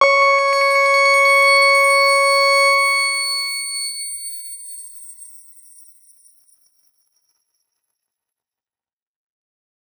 X_Grain-C#5-ff.wav